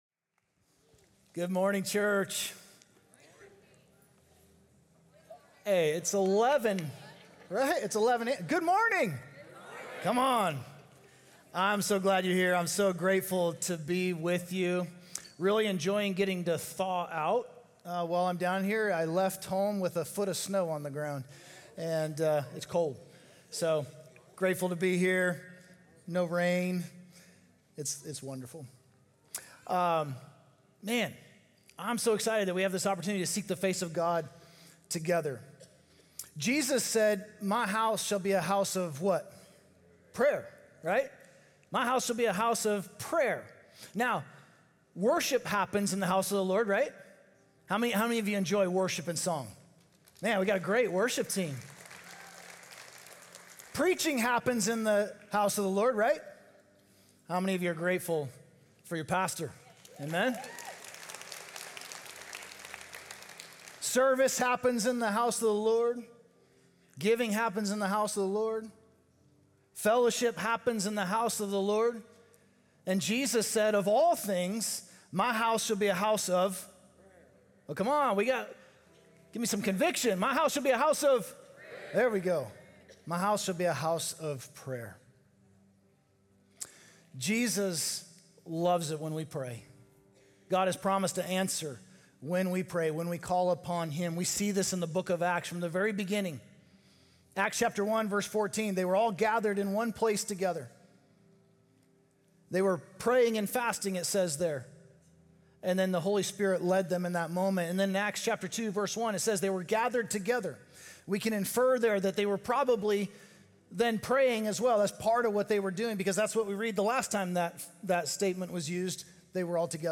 Wednesday Sermons – Media Player